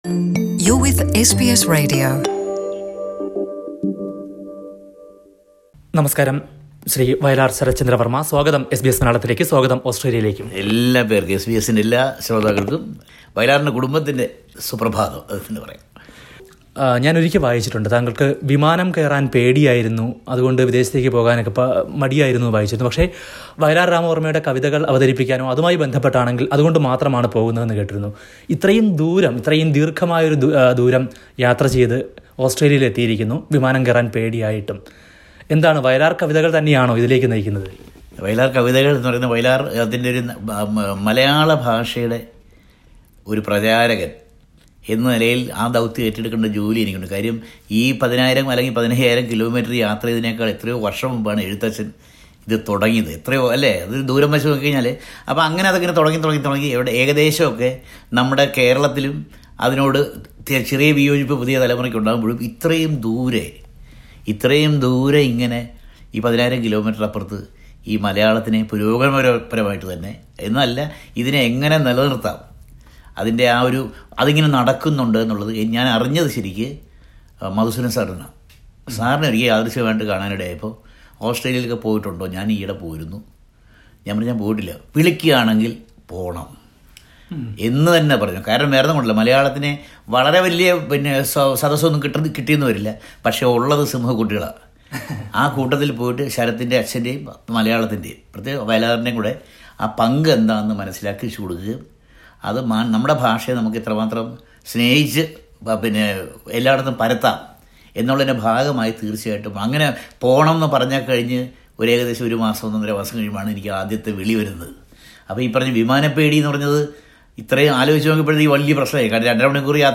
Noted lyricist Vayalar Saratchandra Varma, son of Malayalam's most loved poet Vayalar Rama Varma, talks to SBS Malayalam during his Australian tour.